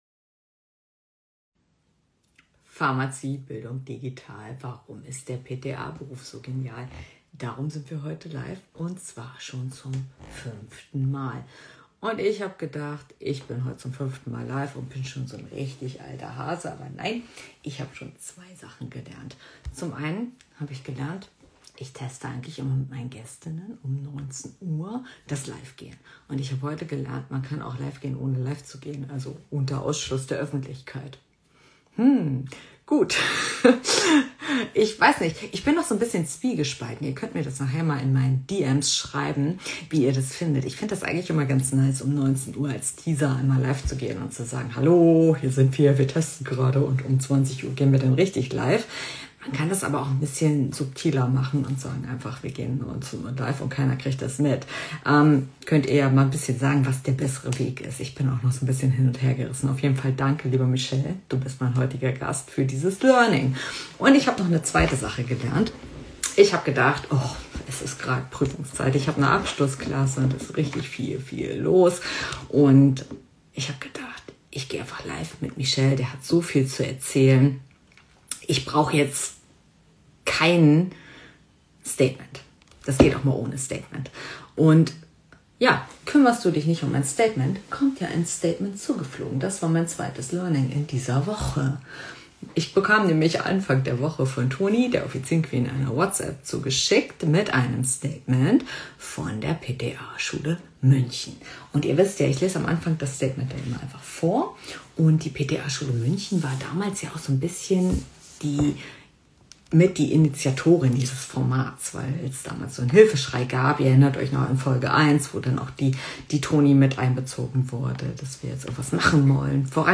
LIVE-TALK